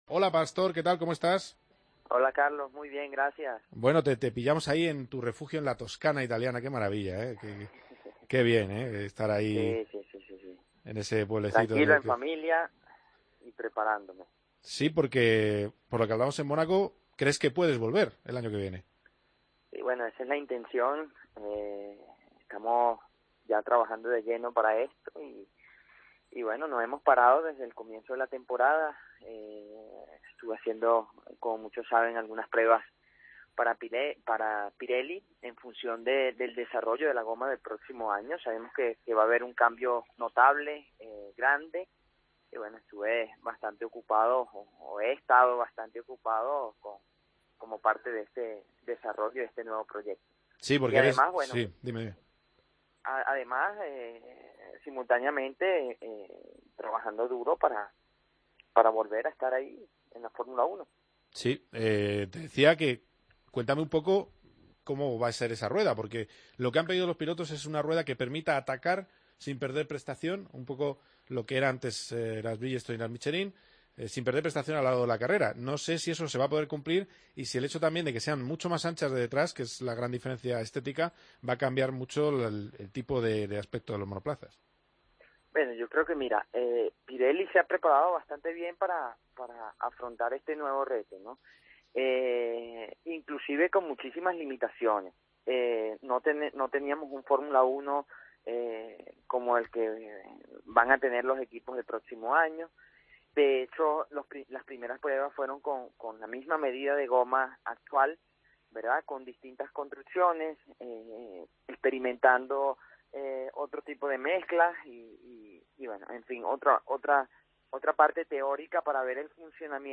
Entrevista a Pastor Maldonado en COPE GP